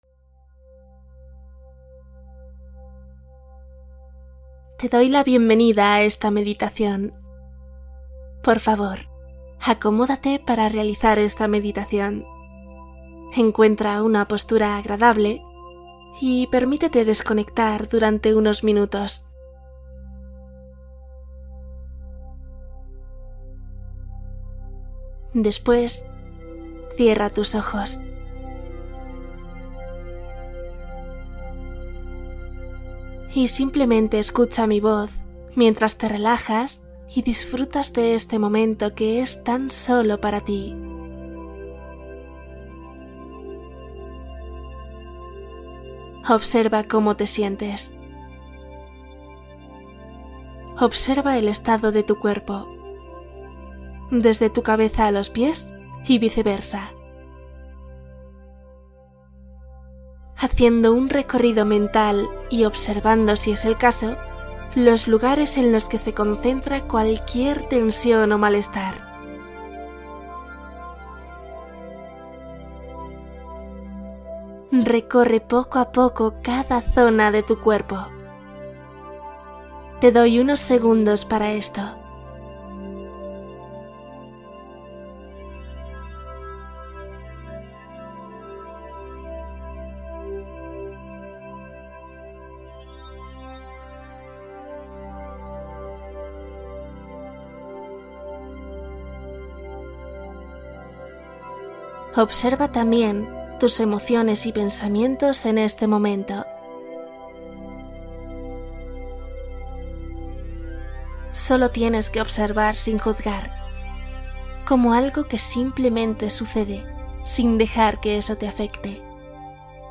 Yo Soy, Yo Quiero, Yo Puedo: meditación de enfoque y determinación